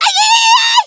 nita_ulti_vo_01.wav